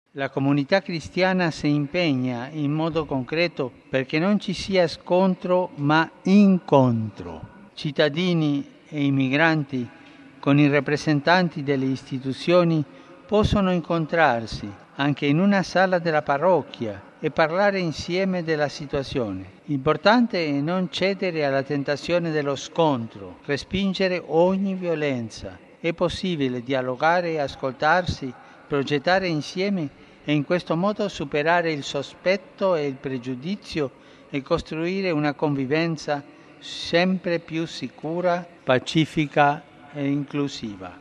Dopo la preghiera dell’Angelus, Papa Francesco ha ricordato le “tensioni piuttosto forti tra residenti e immigrati” in questi giorni a Roma: “Sono fatti – ha detto - che accadono in diverse città europee, specialmente in quartieri periferici segnati da altri disagi”.